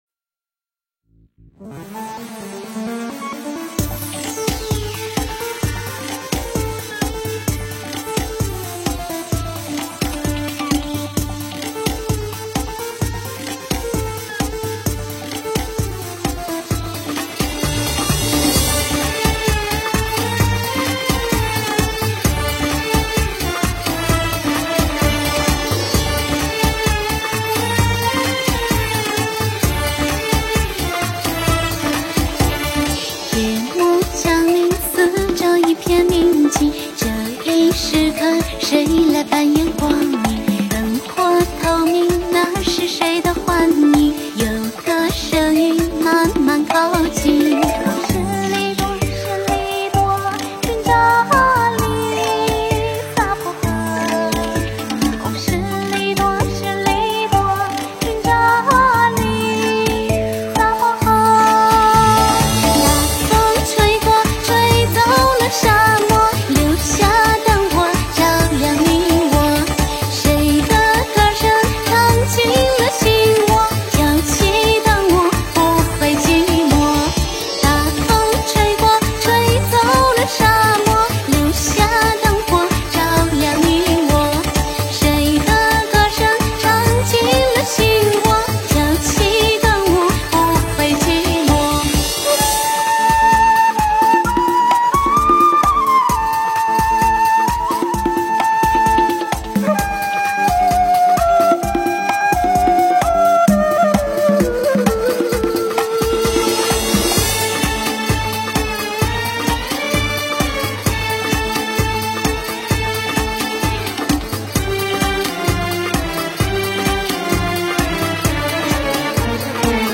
佛音 诵经 佛教音乐 返回列表 上一篇： 忏悔文 下一篇： 思源 相关文章 静止世界--佛教音乐(世界禅风篇